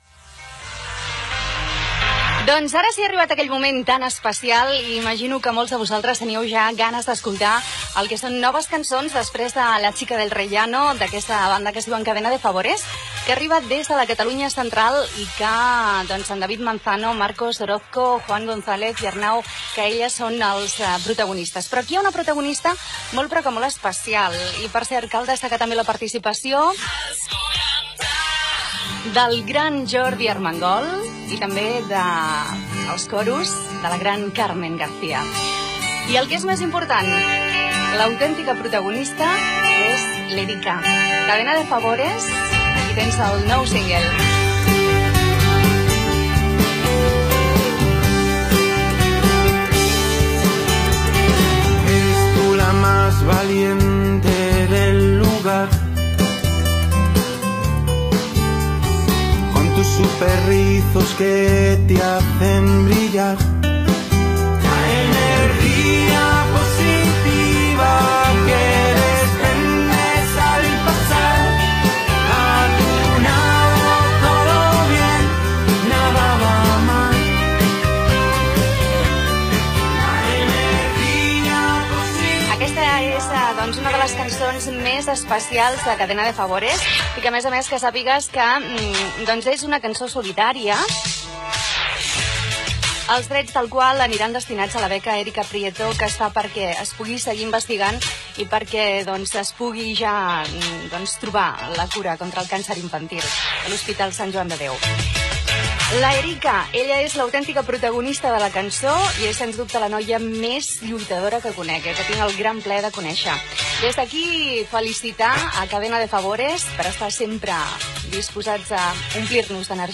Comentari, presentació d'un tema musical, tema musical, comentari sobre el càncer i indicatiu de la ràdio
Musical